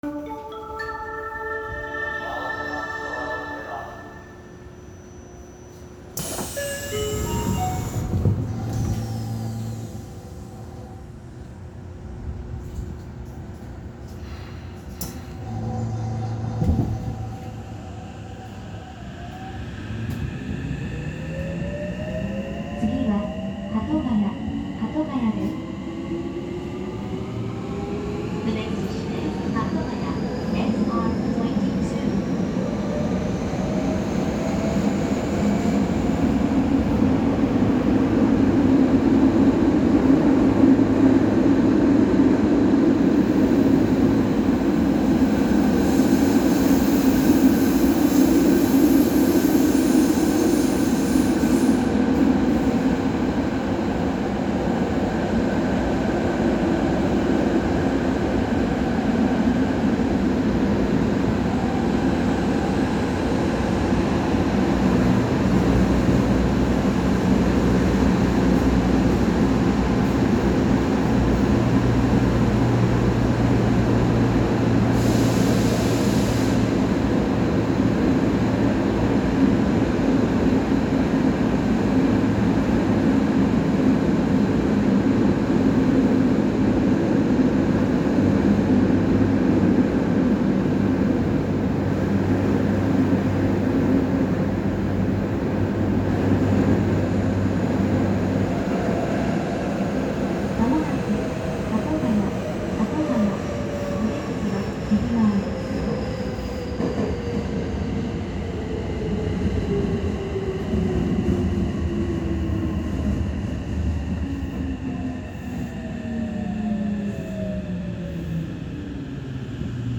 ・9000系三菱IGBT 後期走行音
【埼玉高速線】新井宿→鳩ヶ谷
三菱のモーターのうち、後期の車両の音はややモーター音が目立つようになりました。